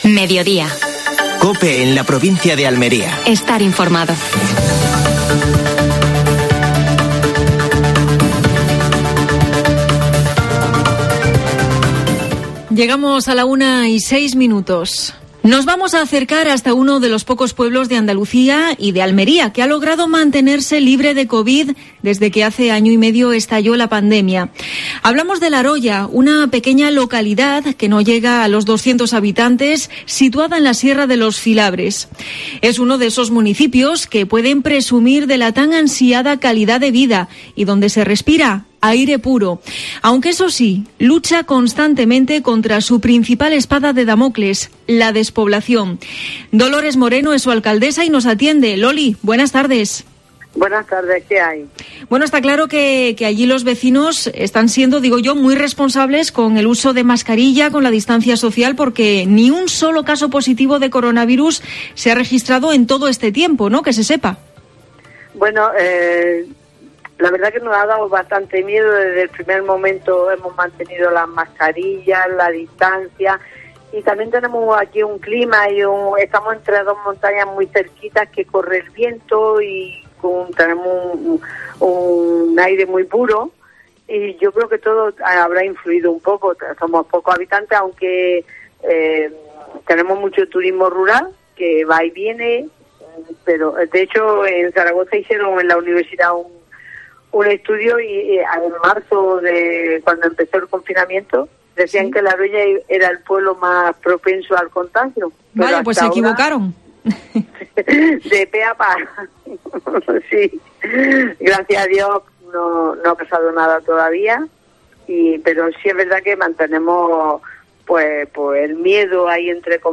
AUDIO: Actualidad en Almería. Entrevista a la alcaldesa de Laroya (municipio libre de covid-19). Victoria de la UDA en el estreno liguero y liderato....